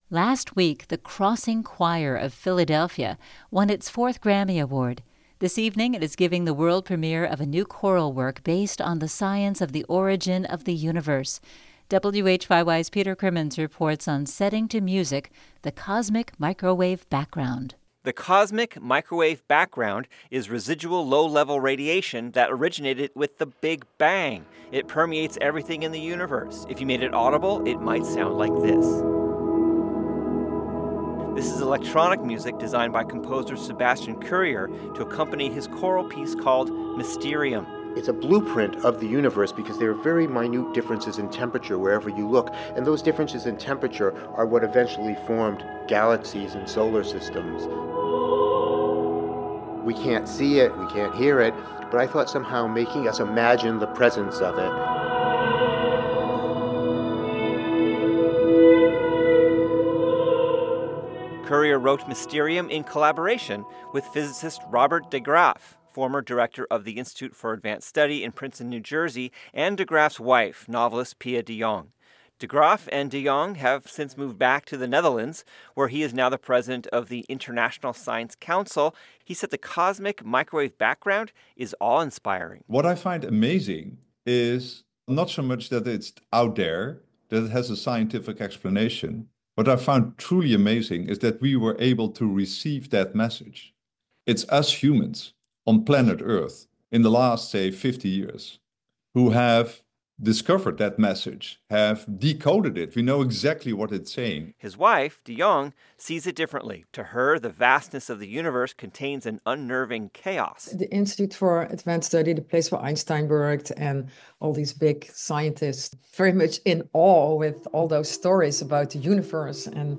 It is the second of four concerts in The Crossing’s annual Month of Moderns summer festival, all of them performed outside using a series of 24 6-foot speakers (that is, roughly human-sized) wired to the 24 singers’ individual mics.